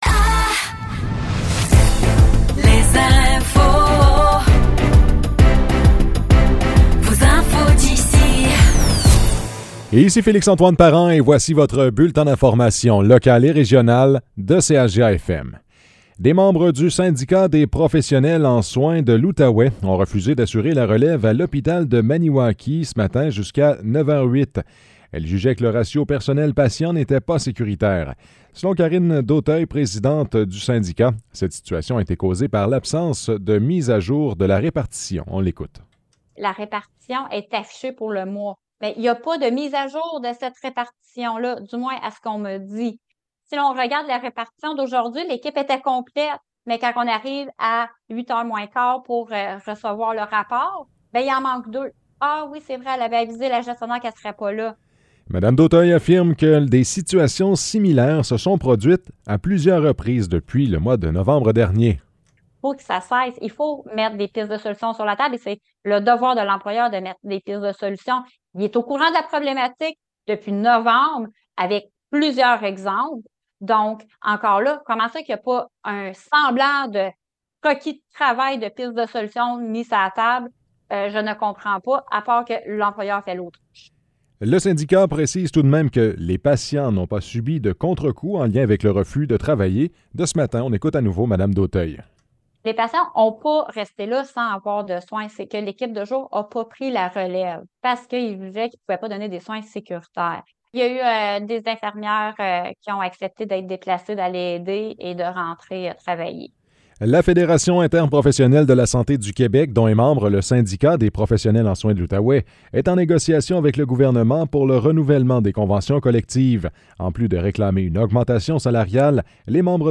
Nouvelles locales - 25 septembre 2023 - 12 h